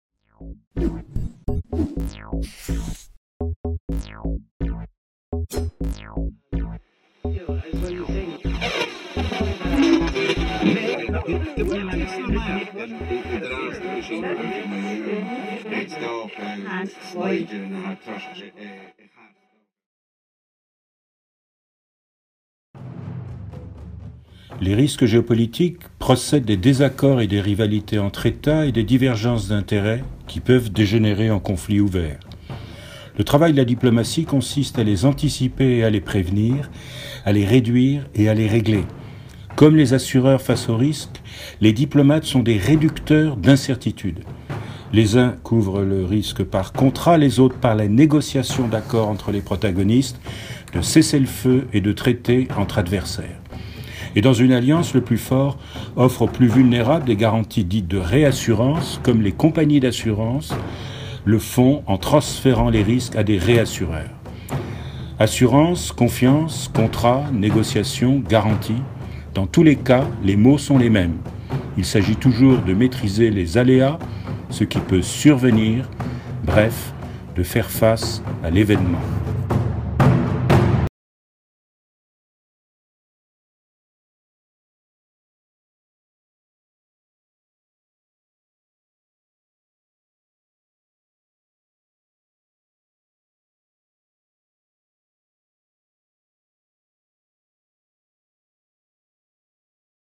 Michel Foucher, géographe, diplomate et essayiste, titulaire de la chaire de Géopolitique appliquée du Collège d'études mondiales de la FMSH, s'exprime dans le cadre du Festival des Idées 2017 sur le thème "l'amour du risque".